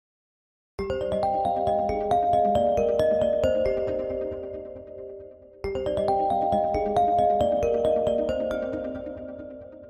stock tones
crisp, clear, and perfect for calls.